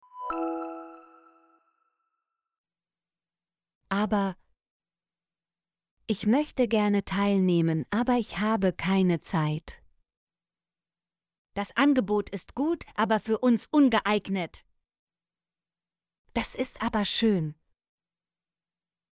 aber - Example Sentences & Pronunciation, German Frequency List